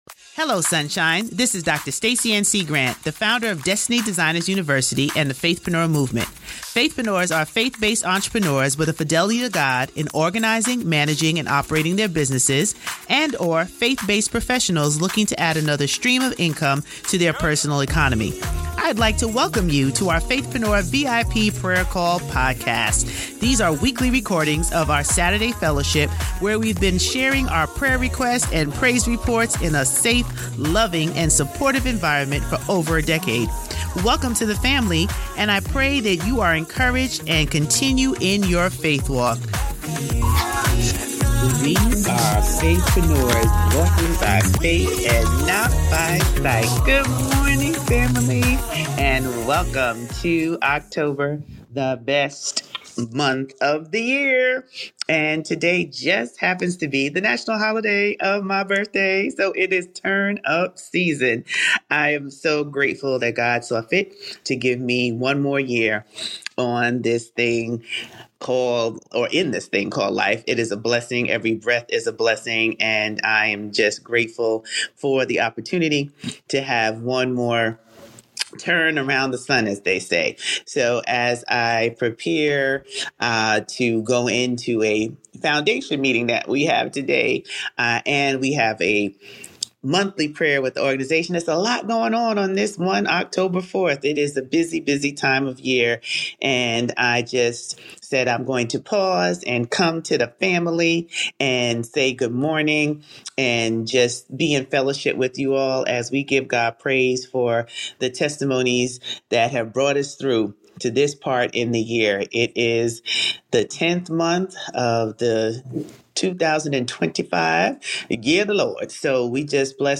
These are recordings of our weekly Saturday’s fellowship where we have been sharing our prayer requests & praise reports in a safe, loving & supportive environment for over a decade.